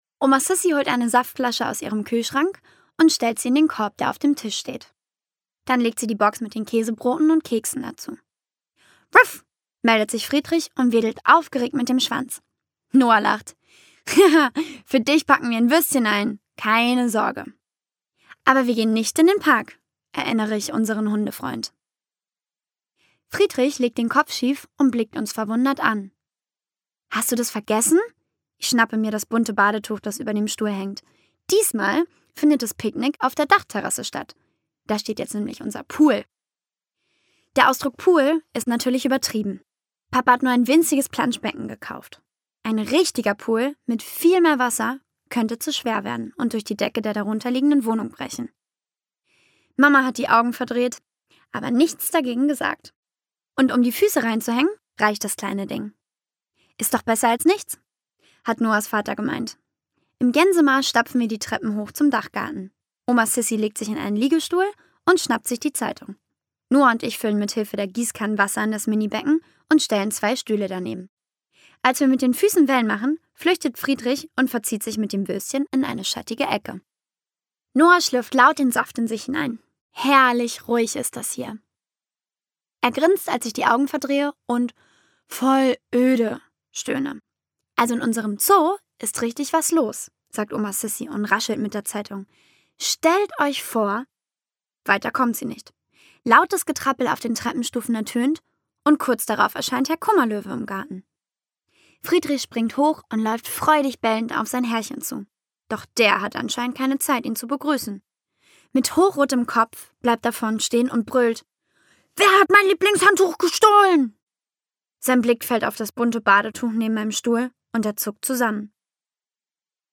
Wunschbüro Edda – Teil 5: Tatort Parkstraße Ungekürzte Lesung mit Lea van Acken
lea van Acken (Sprecher)